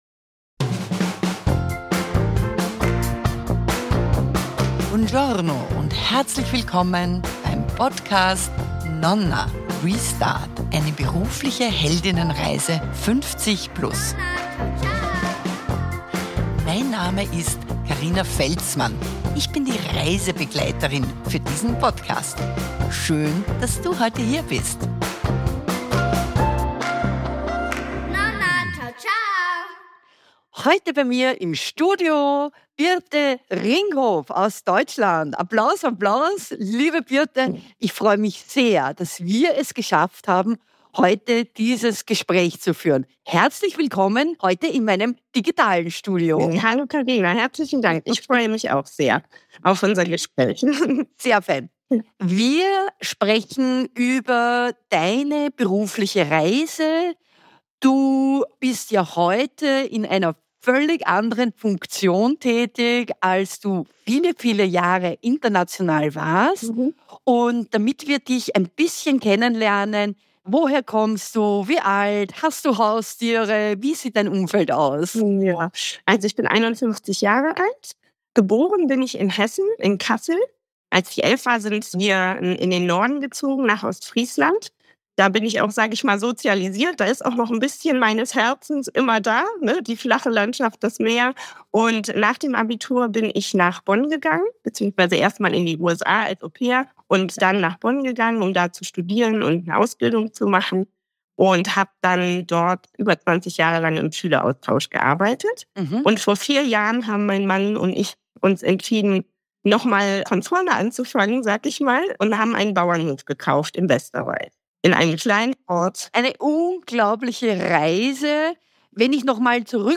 Ein Gespräch über Mut zum radikalen Wandel, das Wiederentdecken von Naturverbundenheit – und warum es nie zu spät ist, ein ganz neues Kapitel aufzuschlagen.